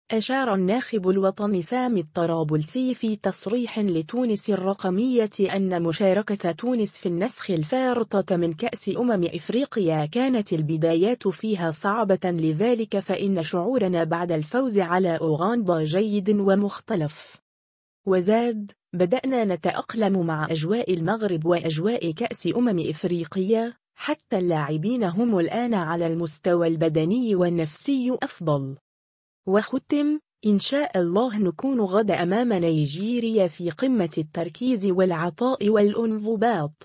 أشار الناخب الوطني سامي الطرابلسي في تصريح لتونس الرقمية أنّ مشاركة تونس في النسخ الفارطة من كأس أمم إفريقيا كانت البدايات فيها صعبة لذلك فإنّ شعورنا بعد الفوز على أوغندا جيّد و مختلف.